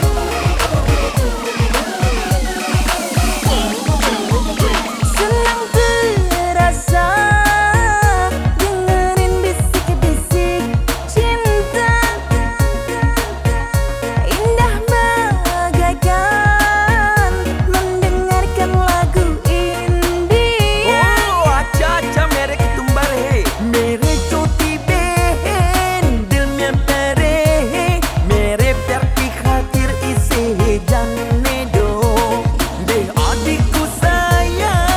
Electronic
Жанр: Электроника